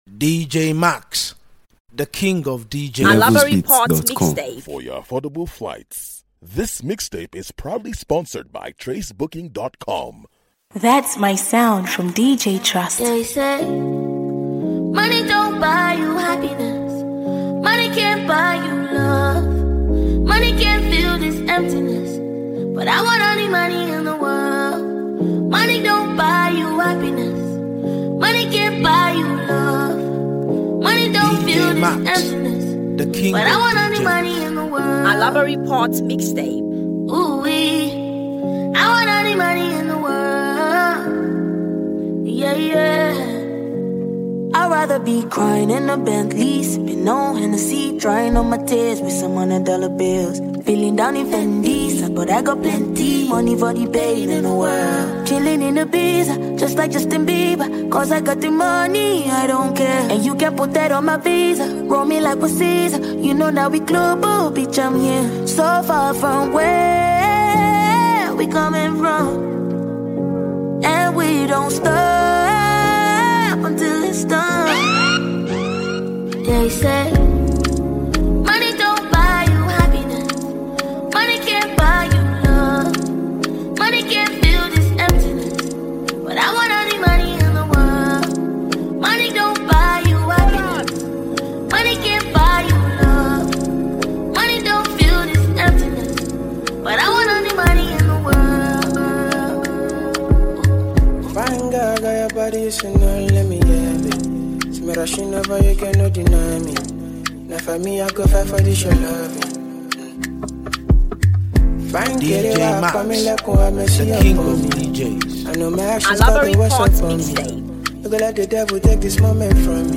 Mp3 Download African songs
mixtape